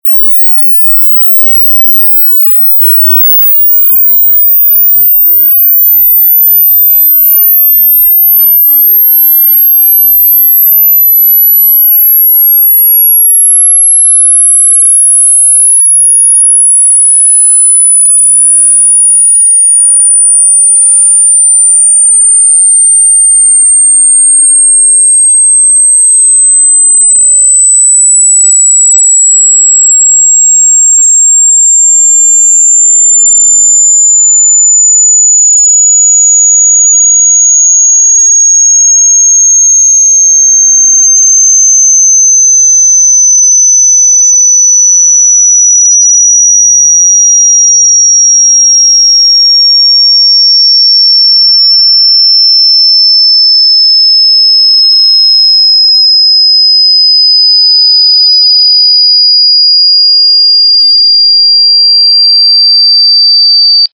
Ультразвук для отпугивания блох